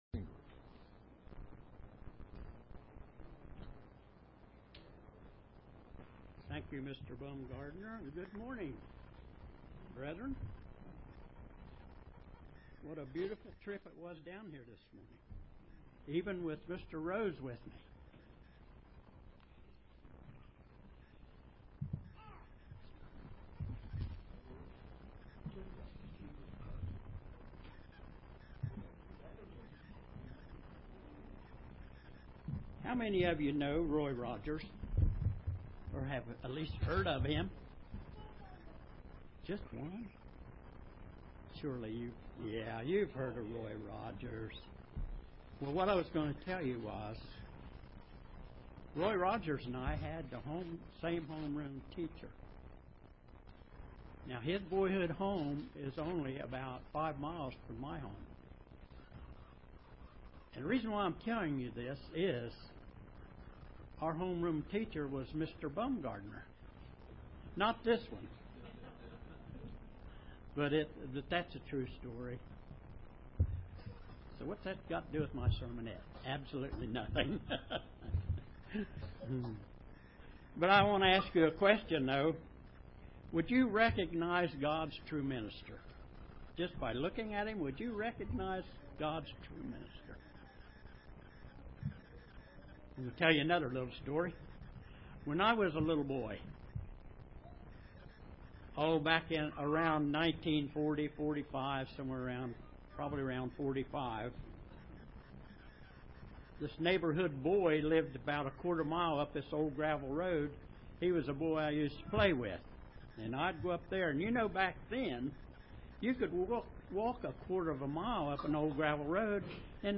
Given in Paintsville, KY
UCG Sermon Studying the bible?